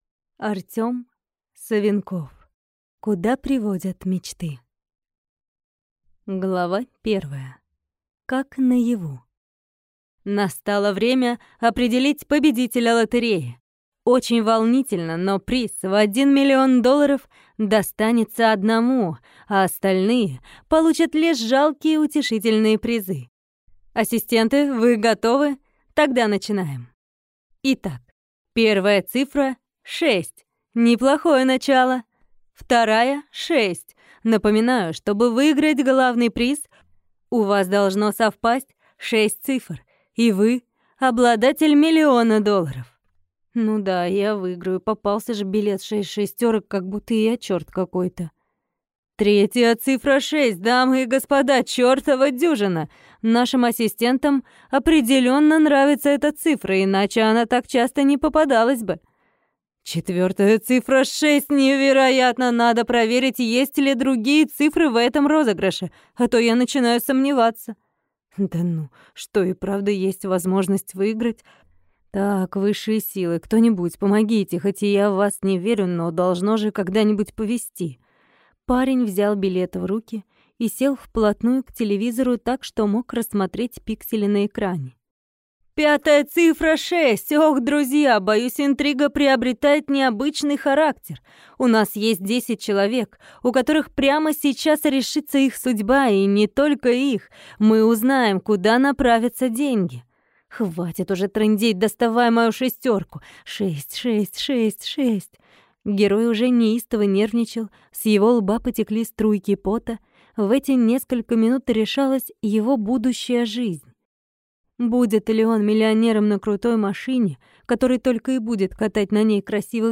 Аудиокнига Куда приводят мечты | Библиотека аудиокниг